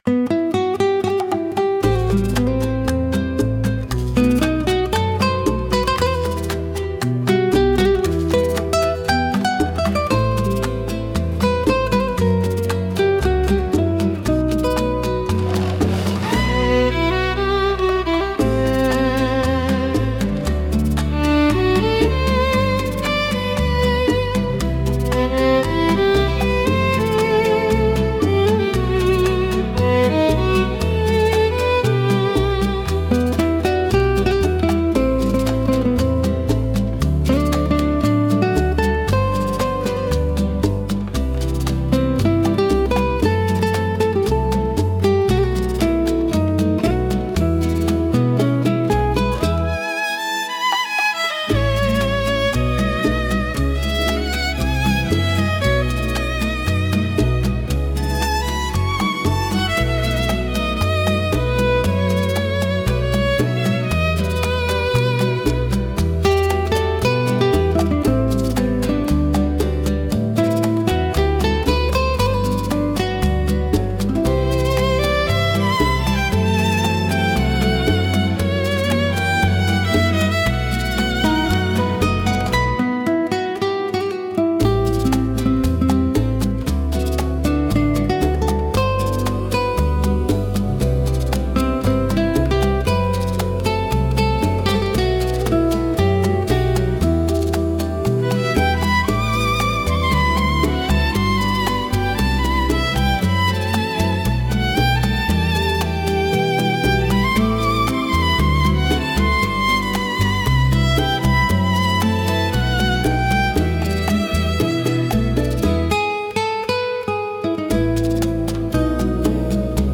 (INSTRUMENTAL)